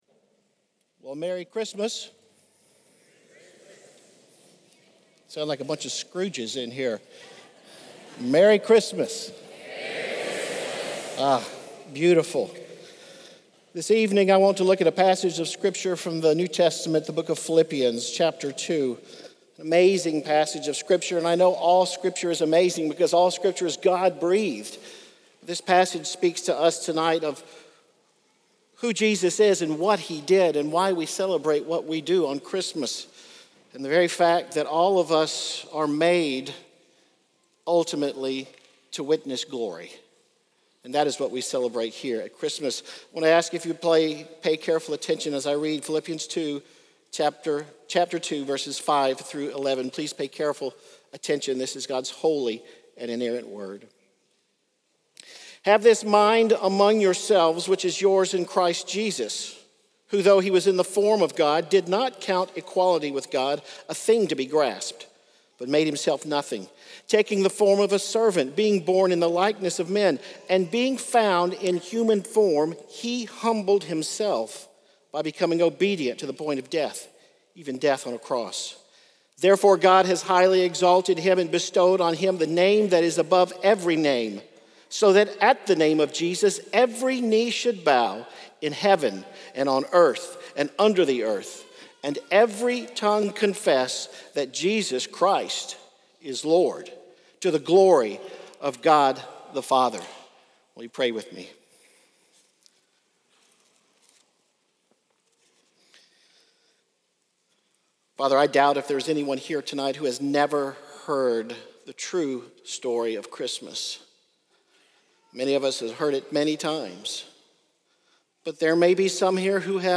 2024 in Sunday Sermons